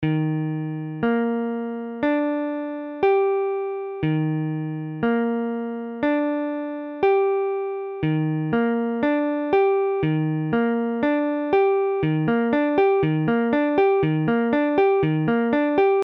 Tablature Eb7M.abcEb7M : accord de Si bémol septième majeure
Mesure : 4/4
Tempo : 1/4=60
A la guitare, on réalise souvent les accords de quatre notes en plaçant la tierce à l'octave.
Forme fondamentale : tonique quinte septième majeure tierce majeure
Eb7M.mp3